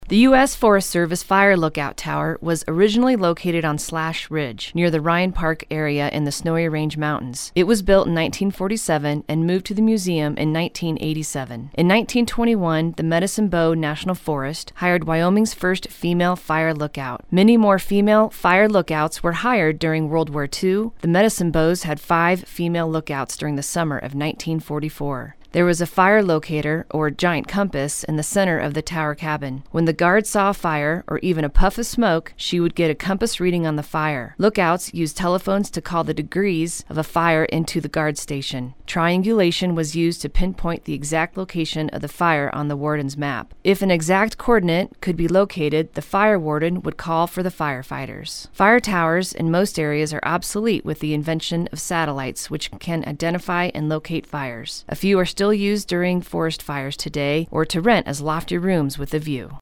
Audio Tour: